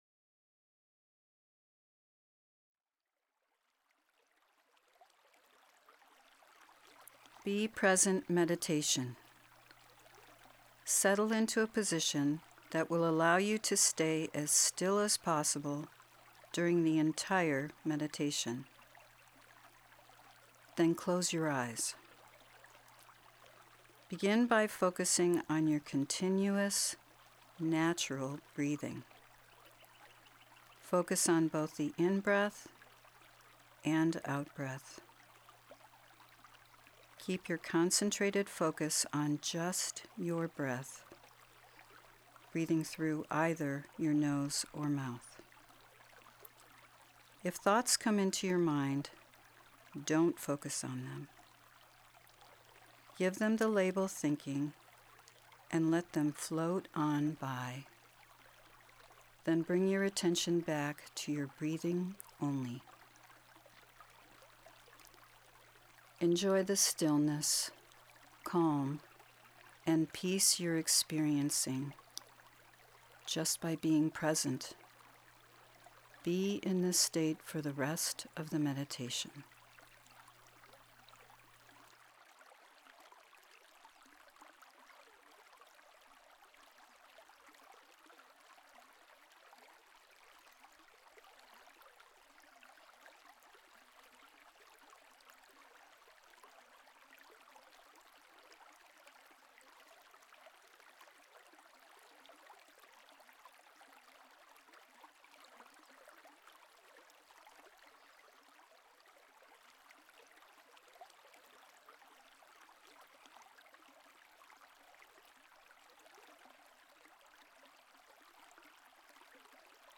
Download Meditation MP3